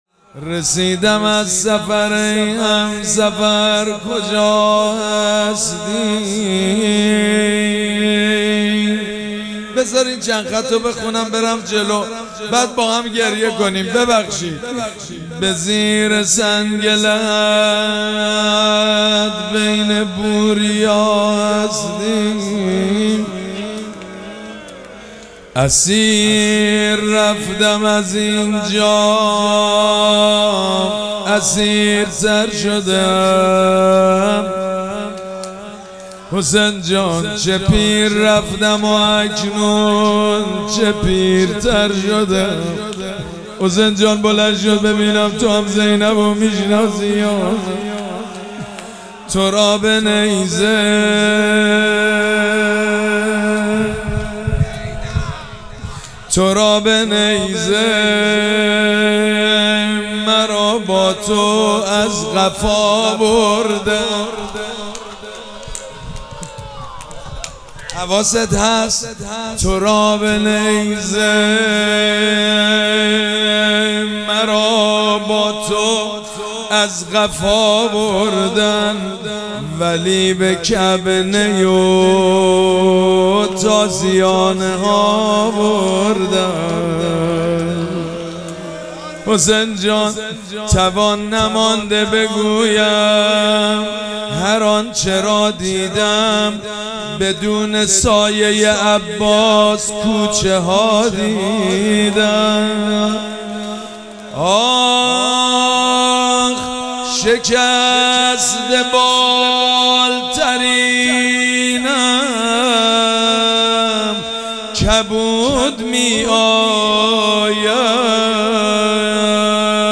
شب پنجم مراسم عزاداری اربعین حسینی ۱۴۴۷
روضه